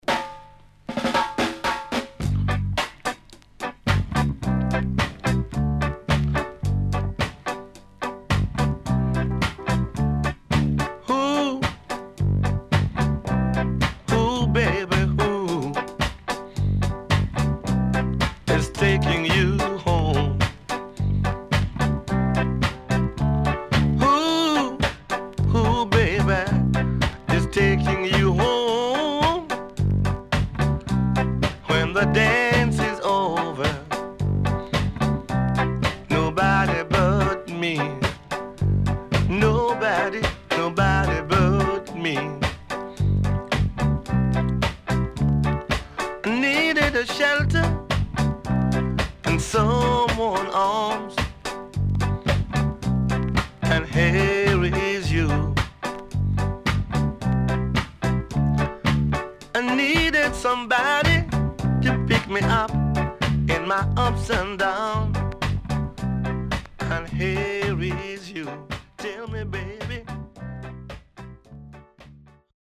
HOME > Back Order [VINTAGE 7inch]  >  EARLY REGGAE
70年 W-Side Good Vocal
SIDE A:少しチリノイズ入ります。